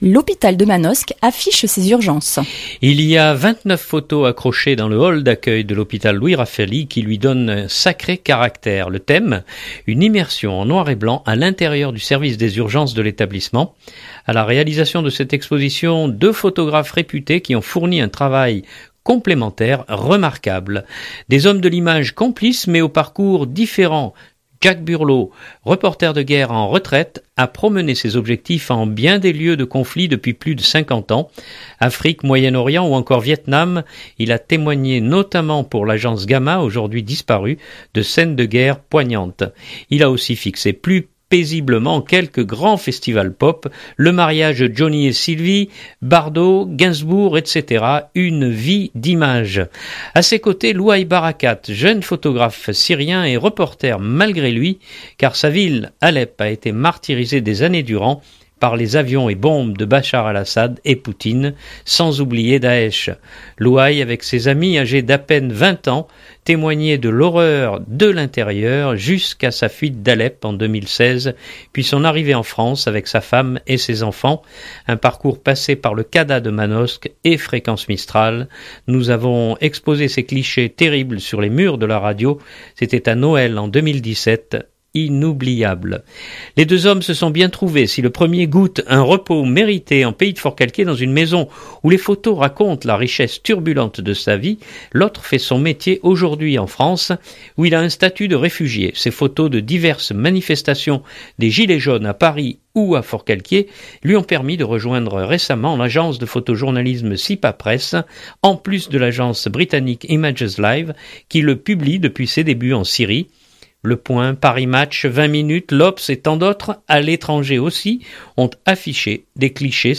reportage urgences 2019-06-06.mp3 (3.7 Mo)